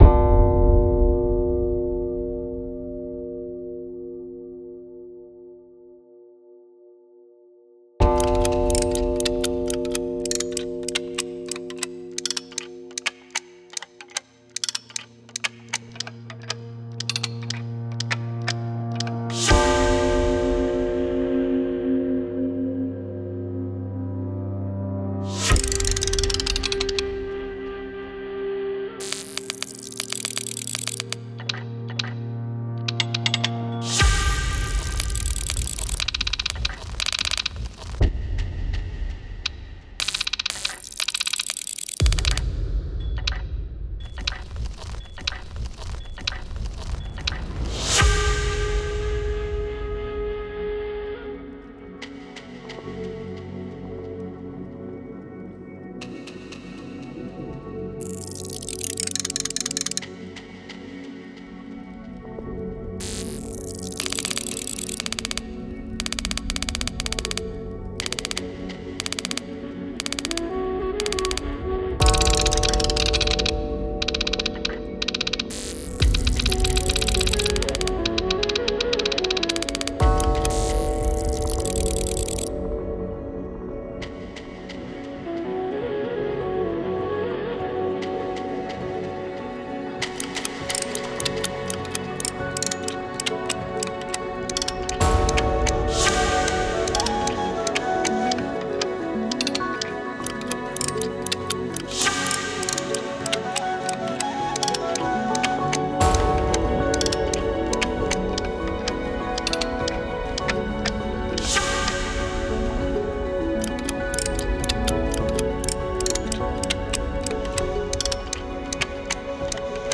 «Казан Кремле» музей-тыюлыгы җирлеген тулыландыручы тавыш инсталляцияләре үткәннән хәзерге заманга эндәшә, тарихның күп тавышлылыгын кабатлый. Төрле тарихи чорлардан ишетелгән тавышлар вакыйгаларның калейдоскобын җанландыра, вакытның кискен борылышларында Казан Кремле язмышын ачып бирә.
«Сәгать порталы» инсталляциясе